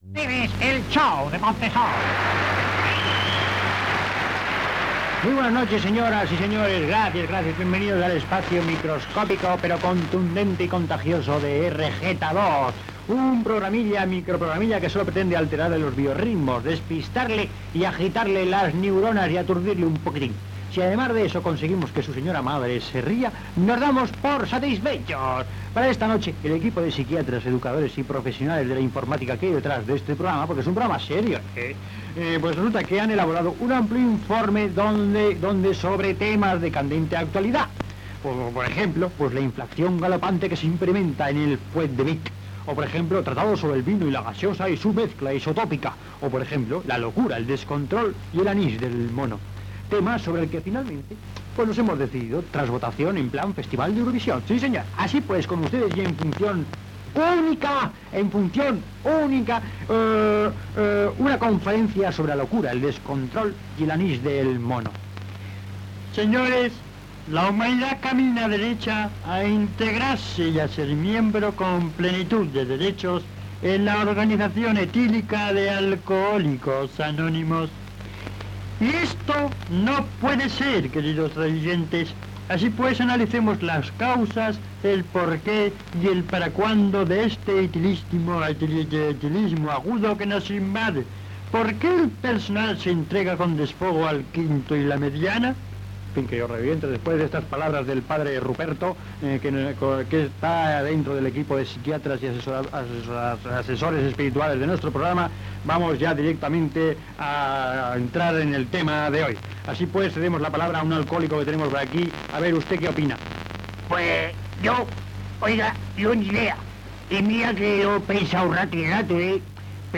"Radio Montesol" presentació i conferència sobre l'alcoholisme, indicatiu del programa i esment a una frase llatina
Entreteniment